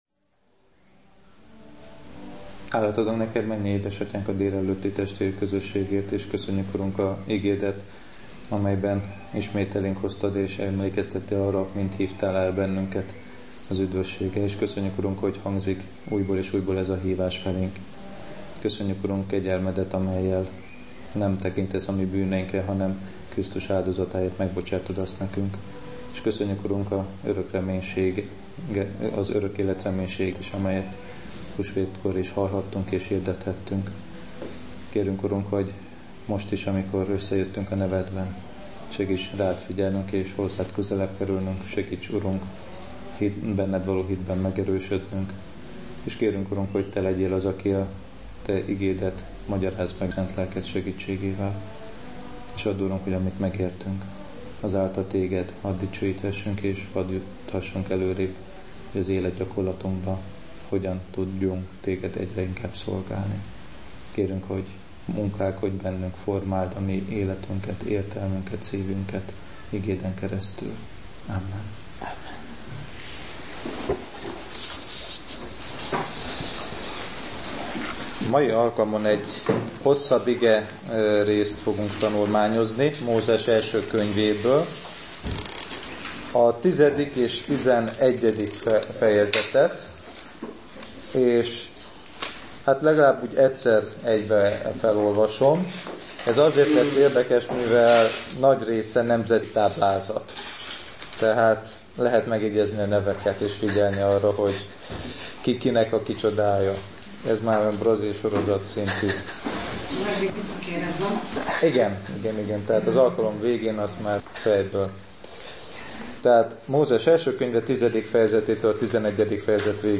Technikai hiba miatt az alkalom vége hiányzik a felvételr?l.